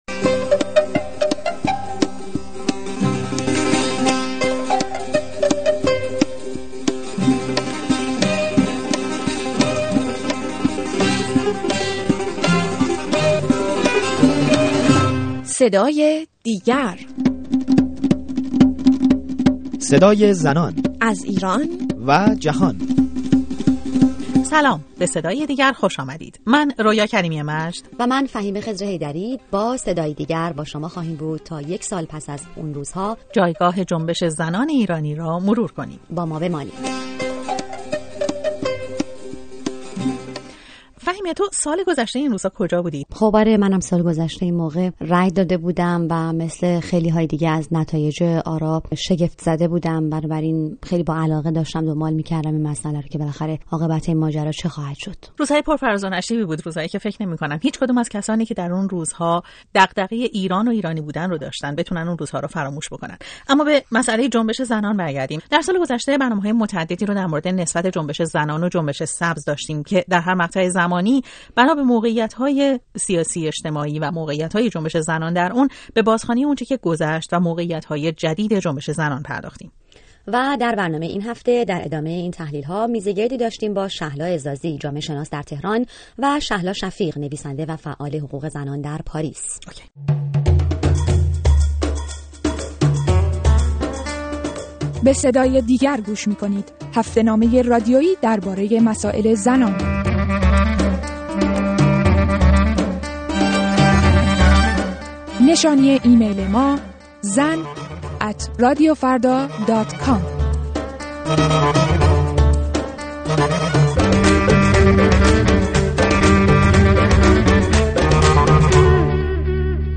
برنامه رادیویی «صدای دیگر» و میزگردی